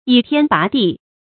倚天拔地 注音： ㄧˇ ㄊㄧㄢ ㄅㄚˊ ㄉㄧˋ 讀音讀法： 意思解釋： 倚天而立，拔地而起。